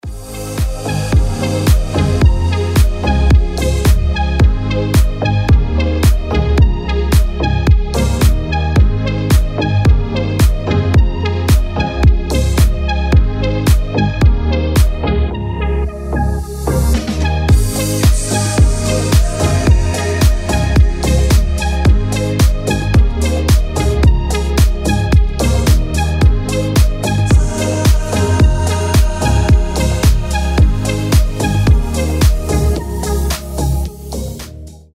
• Качество: 320, Stereo
красивые
deep house
мелодичные